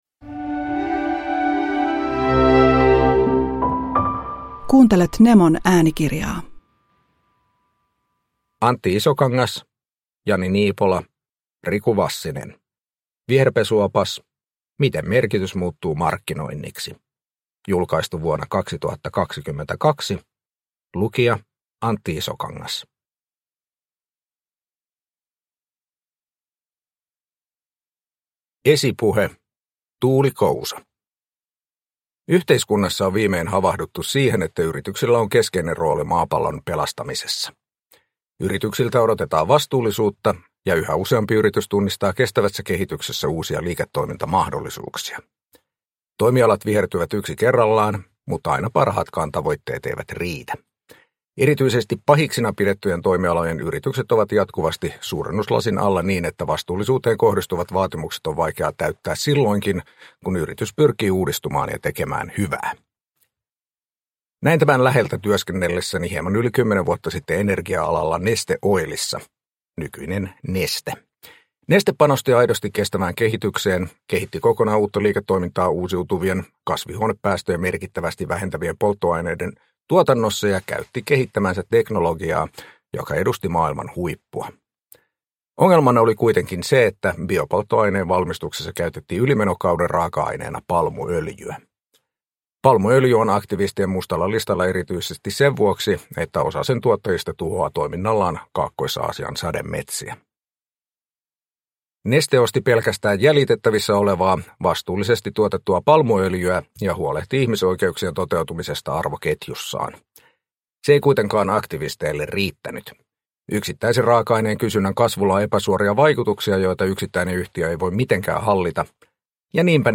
Viherpesuopas – Ljudbok – Laddas ner